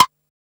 edm-perc-43.wav